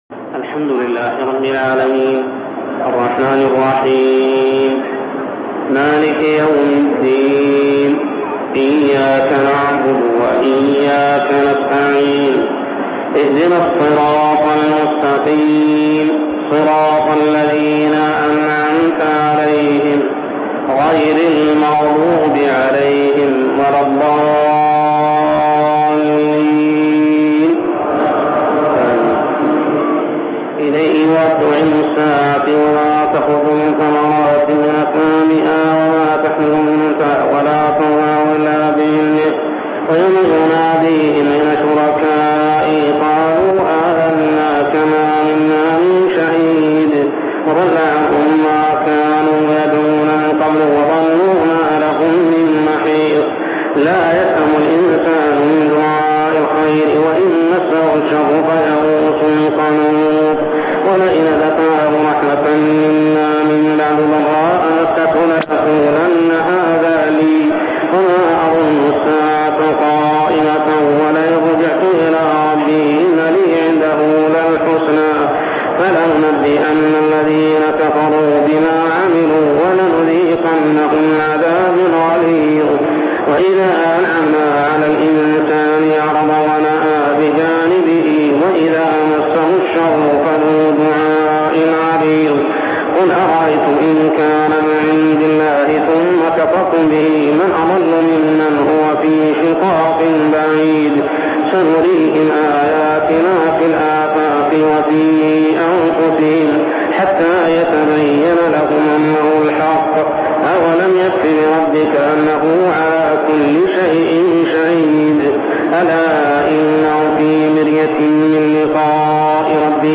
صلاة التراويح عام 1402هـ سور فصلت 47-54 و الشورى كاملة و الزخرف 1-35 ( من الآية 35 من الشورى حتى الآية 7 من الزخرف مفقودة ) | Tarawih prayer Surah Fussilat, Ash-Shura, and Az-Zukhruf > تراويح الحرم المكي عام 1402 🕋 > التراويح - تلاوات الحرمين